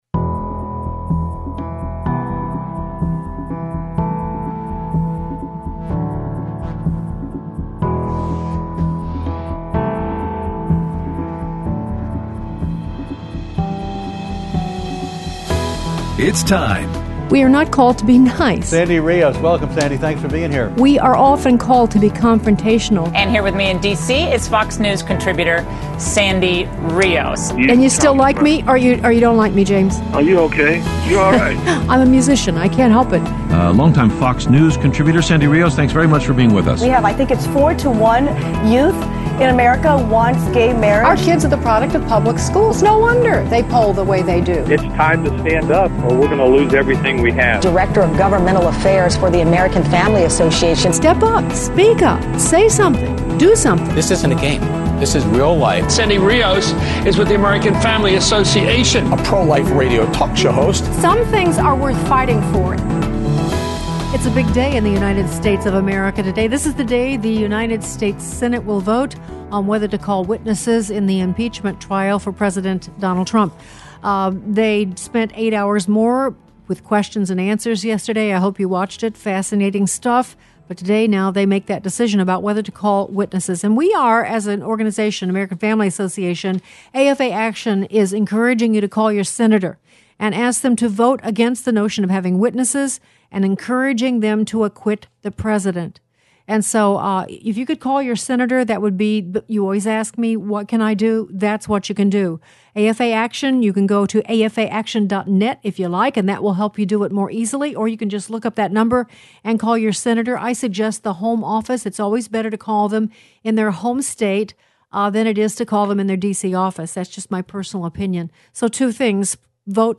Interview with Producer Michael Pack on His New Movie: Created Equal
Aired Friday 1/31/20 on AFR 7:05AM - 8:00AM CST